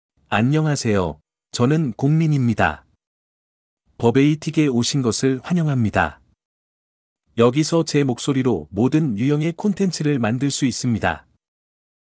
GookMinMale Korean AI voice
GookMin is a male AI voice for Korean (Korea).
Listen to GookMin's male Korean voice.
GookMin delivers clear pronunciation with authentic Korea Korean intonation, making your content sound professionally produced.